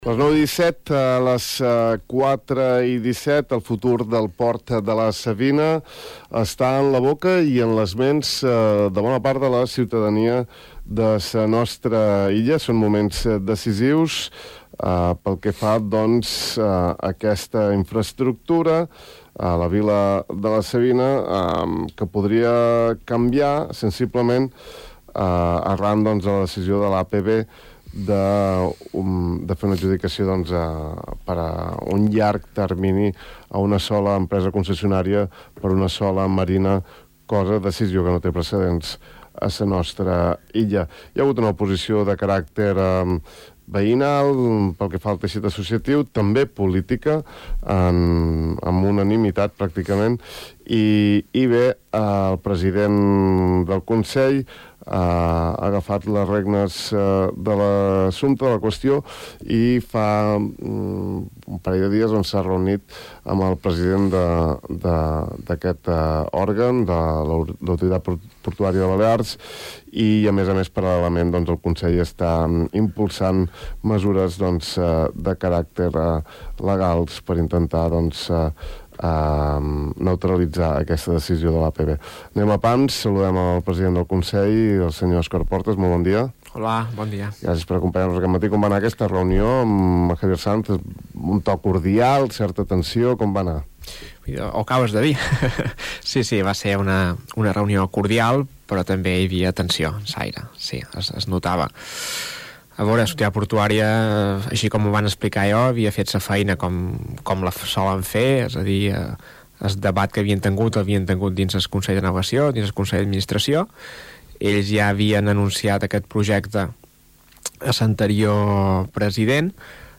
El president del Consell de Formentera, Óscar Portas, ha anunciat a Ràdio Illa que la setmana vinent té previst convocar una sessió del Consell d’Entitats per abordar el futur del port de la Savina, tal com ha sol·licitat la plataforma ciutadana Deim Prou! i també han reivindicat la seva i les altres dos formacions polítiques amb presència al Ple insular.